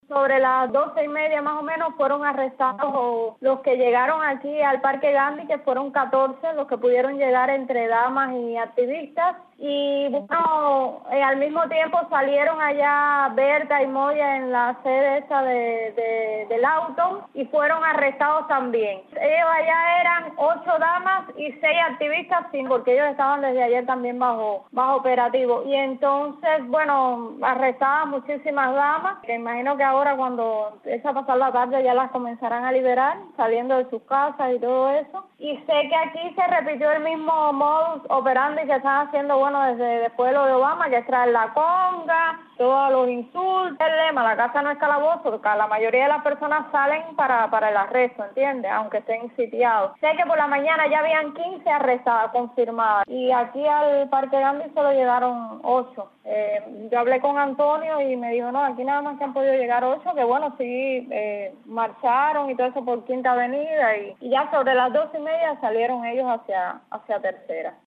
Testimonio de activista sobre los arrestos